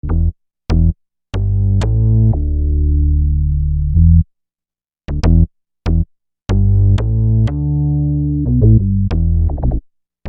Bass 20.wav